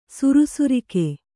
♪ surusurike